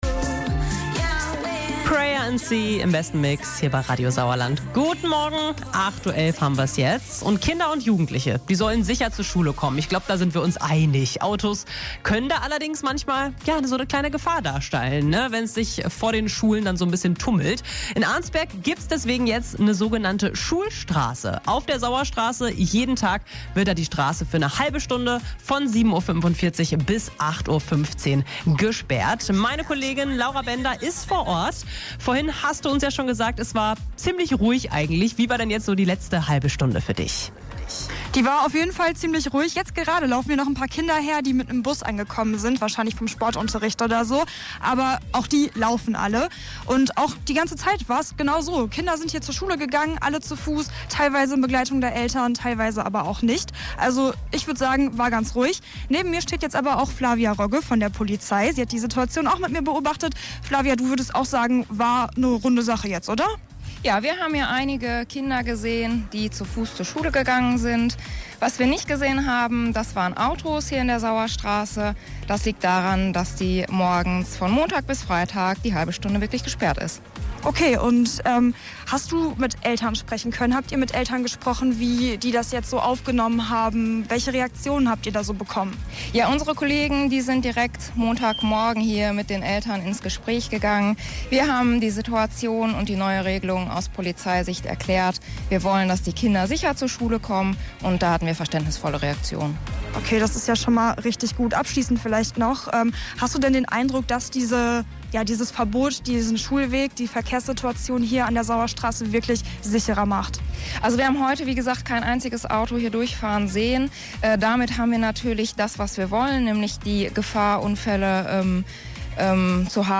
mitschnitt-liveschalte-polizei.mp3